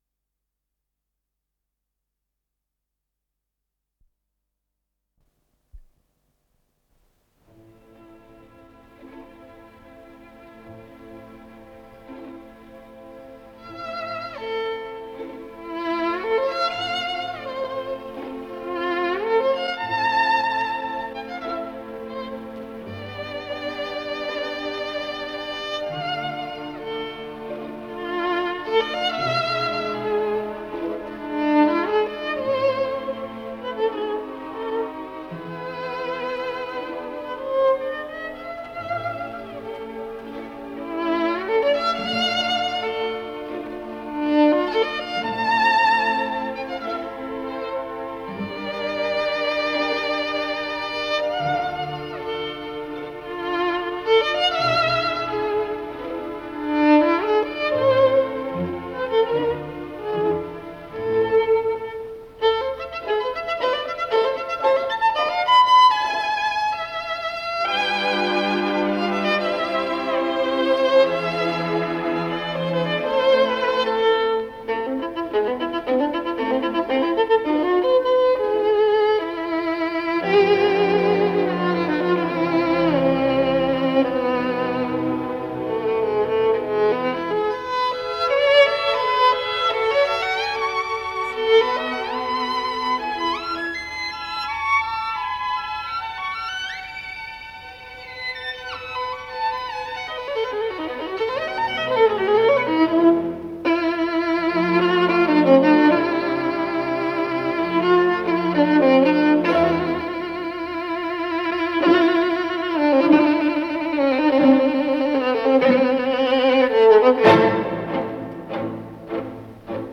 с профессиональной магнитной ленты
ПодзаголовокЛя минор
ИсполнителиДавид Ойстрах - скрипка
АккомпаниментБостонский симфонический оркестр
Дирижёр - Шарл Мюнш
Скорость ленты38 см/с